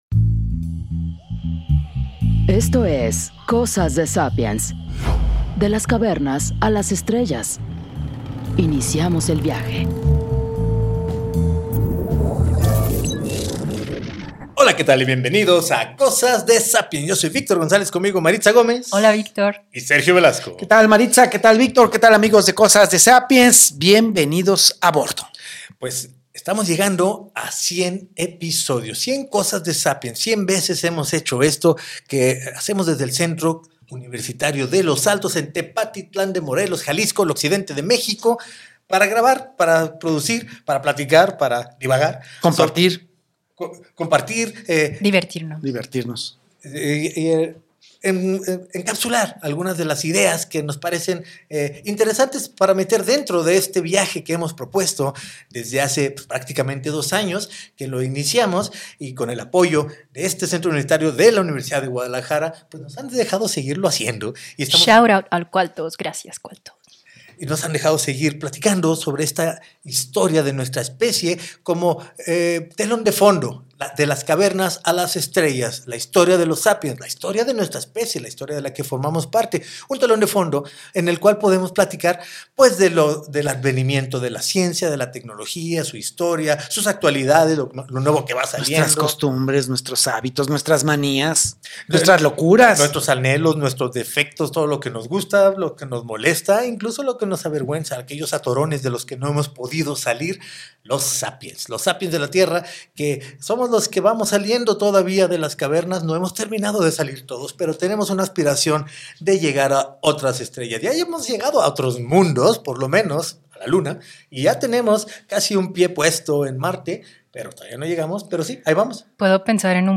En este episodio paseamos por el Centro Universitario de Los Altos donde producimos este podcast y hacemos un recuento de lo que hemos hecho hasta el momento, los temas que nos gustan, las cosas que nos interesan, las que nos motivan y las que nos estresan. Compartimos un poco de lo que para cada uno de nosotros significa ser uno de los sapiens.